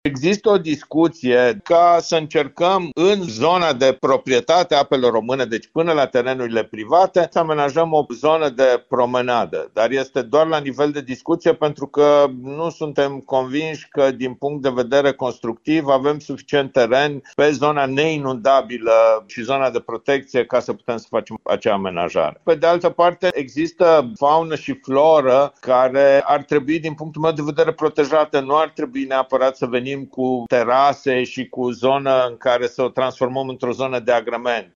Zona se dorește a fi una de promenadă, iar administrația locală nu este de acord cu amenajarea de terase, pentru că ar afecta biodiversitatea, a afirmat primarul Horia Bugarin.